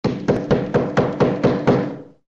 Звуки стуков в дверь
Звук стука в дверь кто-то снова постучал